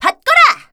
assassin_w_voc_applause02.ogg